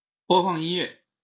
Chinese_Commands_Speech_Data_by_Bluetooth_Headset